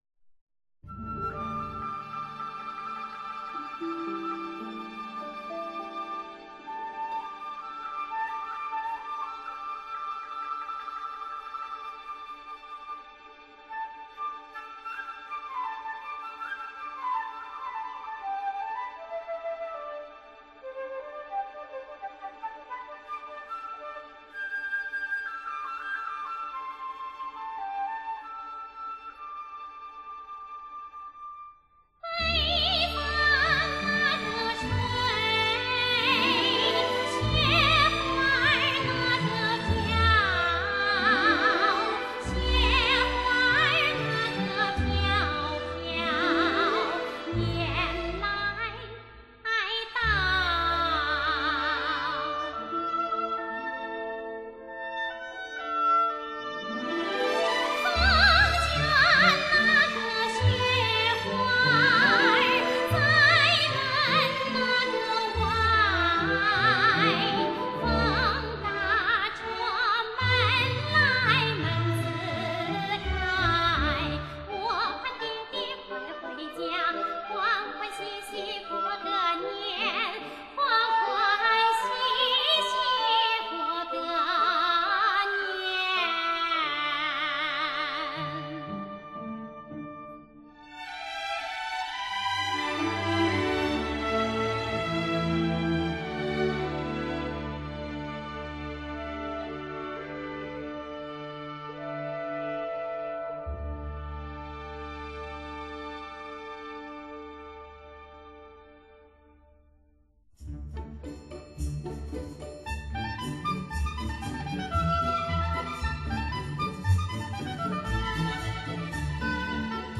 资历时六个月在丹麦进行。
高低频的延伸更趋顺滑自然，以最健康、最发烧的声音来迎合您挑剔的聆听要求。